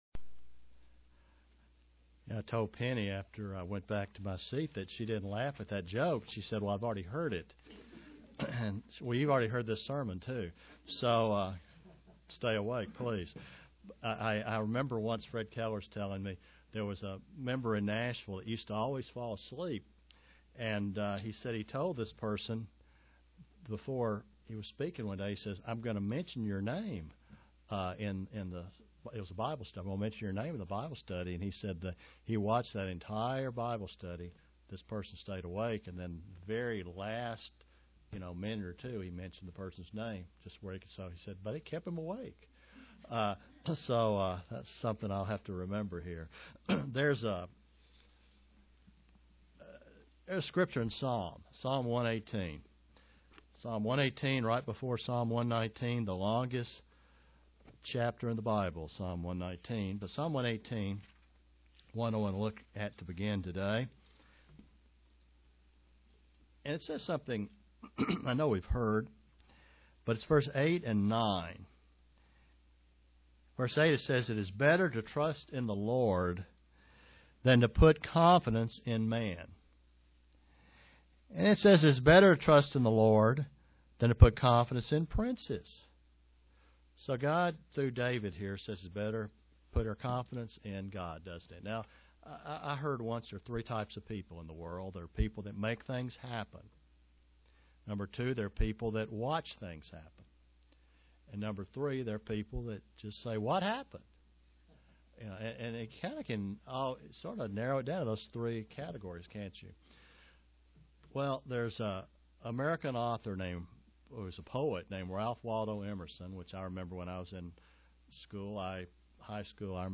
Print Study of the life of the apostle Peter UCG Sermon Studying the bible?
Given in London, KY